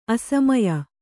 ♪ asamaya